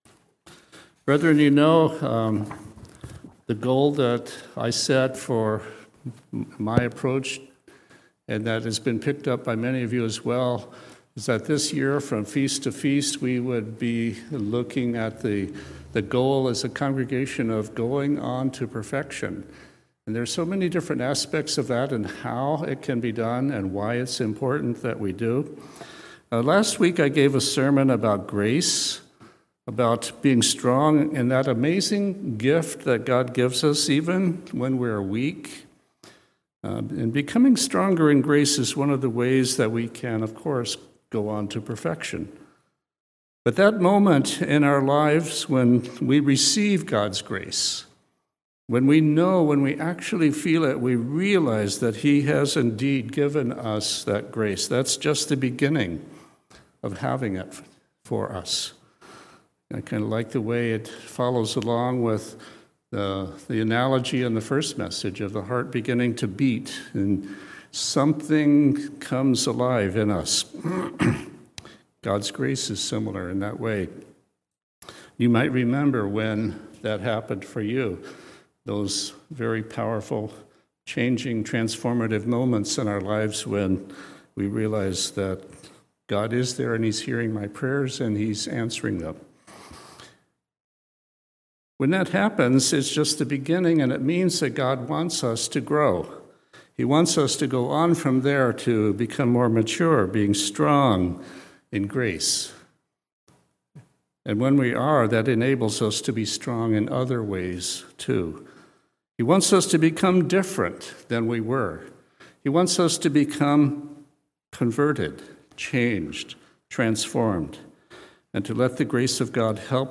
Sermons
Given in Olympia, WA Tacoma, WA